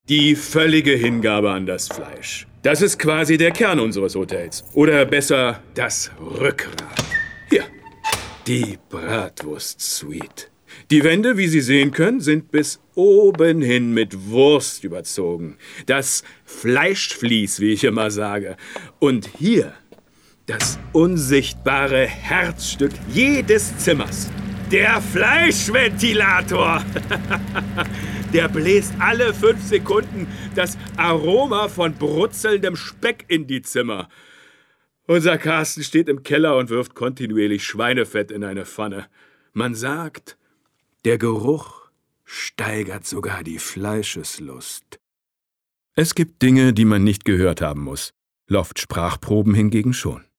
sehr variabel, markant
Mittel plus (35-65)
Commercial (Werbung)